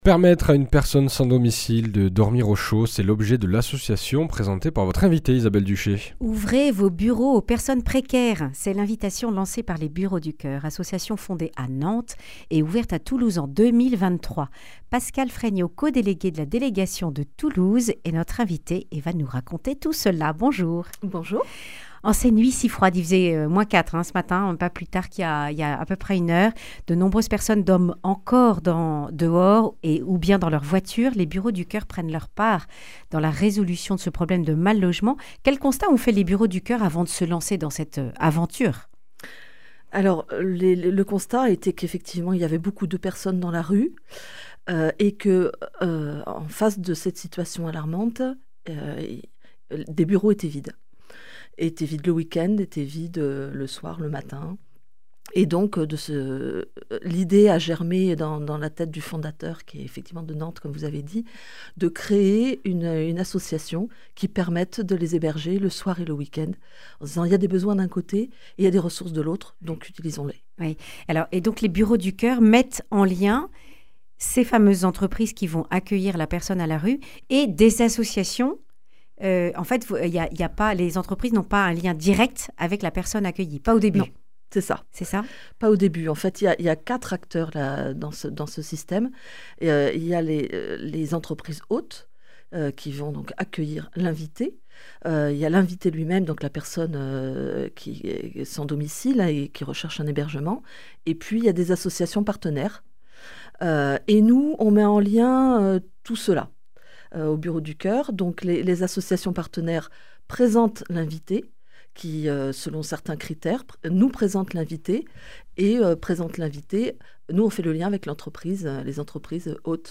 Accueil \ Emissions \ Information \ Régionale \ Le grand entretien \ Ouvrez vos bureaux aux personnes précaires !